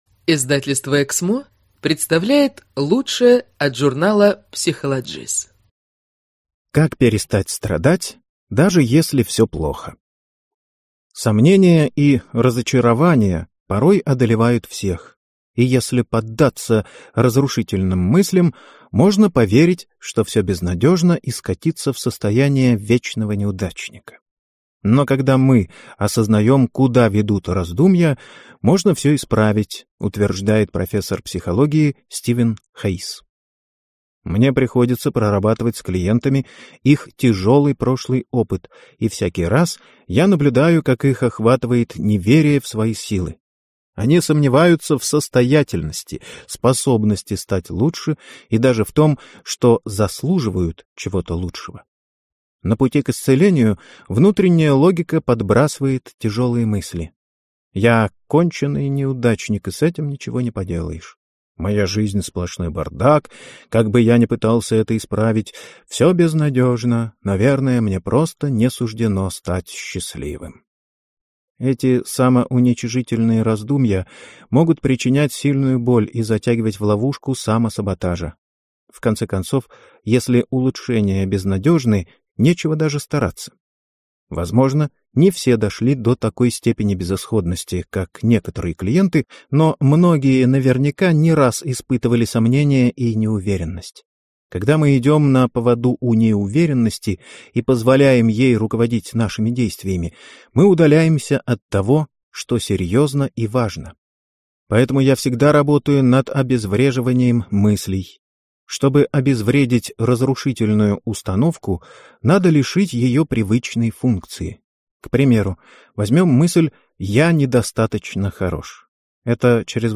Аудиокнига Душевная травма – стимул для роста?
Прослушать и бесплатно скачать фрагмент аудиокниги